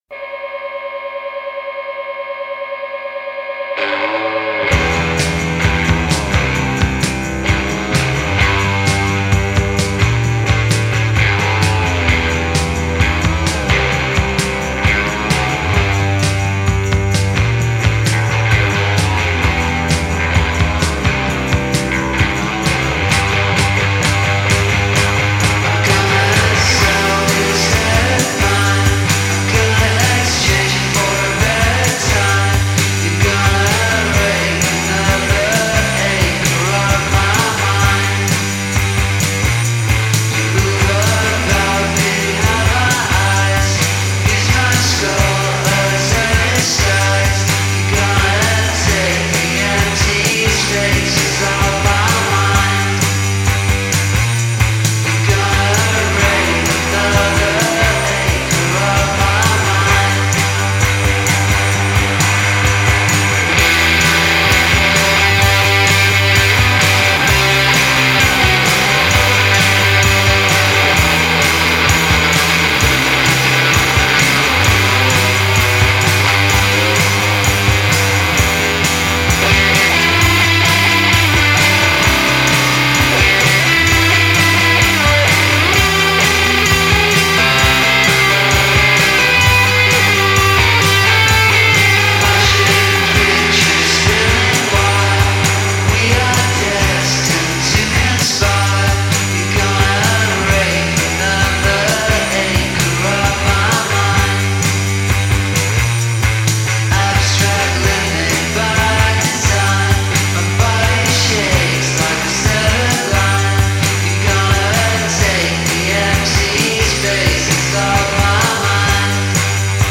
trio proveniente da Brighton